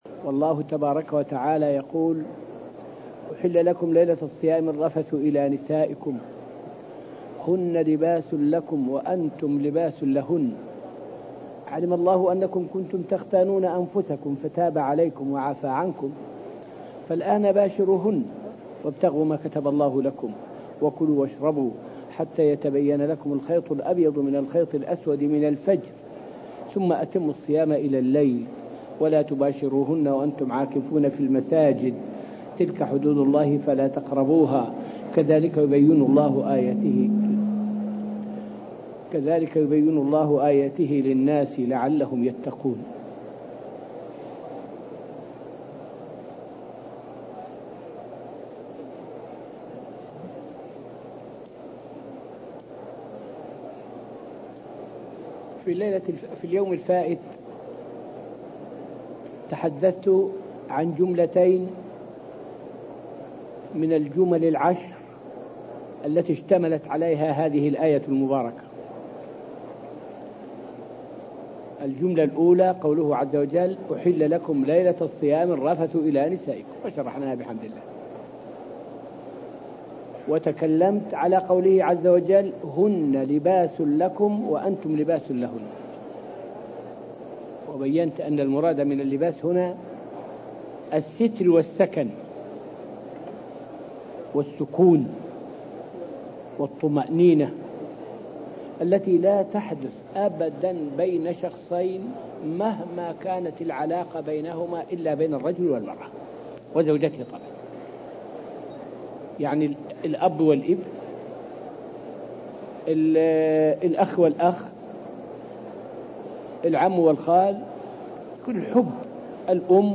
فائدة من الدرس الثامن والعشرون من دروس تفسير سورة البقرة والتي ألقيت في المسجد النبوي الشريف حول تفسير قوله تعالى {فباشروهن وابتغوا ما كتب الله لكم}.